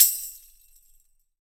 TAMB DUAL-1.wav